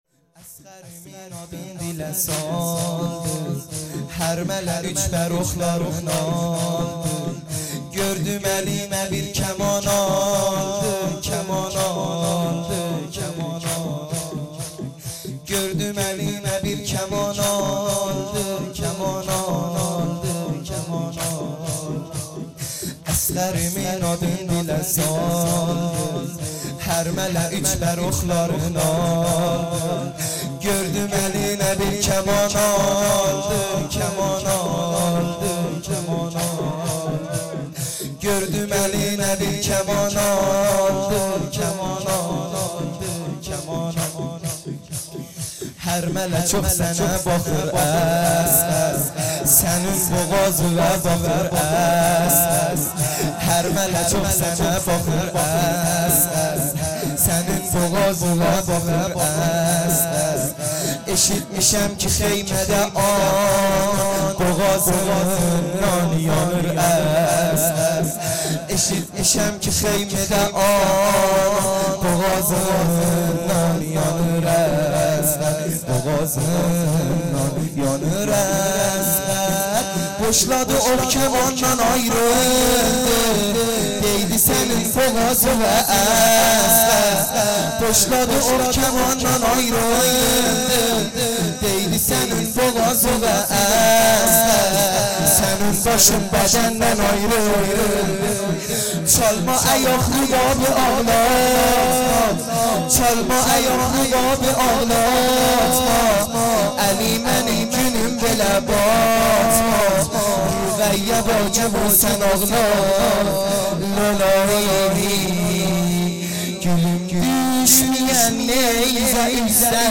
لطمه زنی